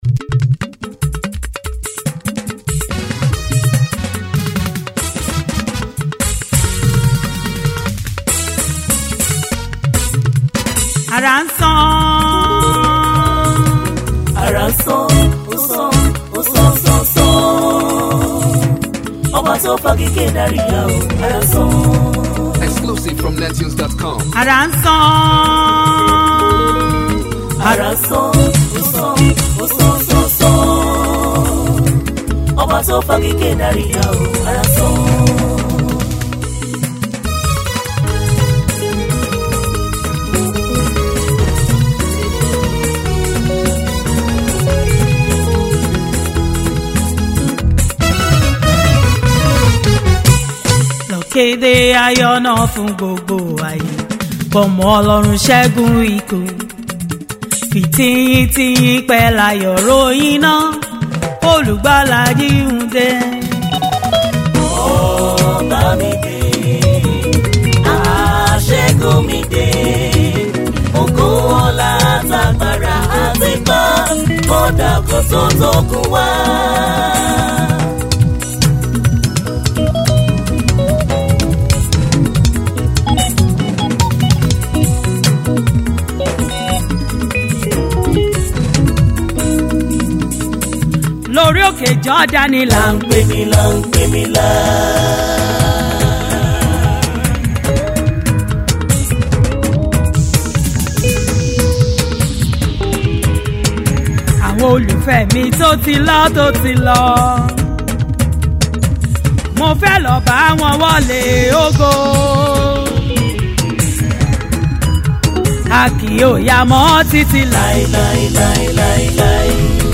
Nigerian gospel singer and songwriter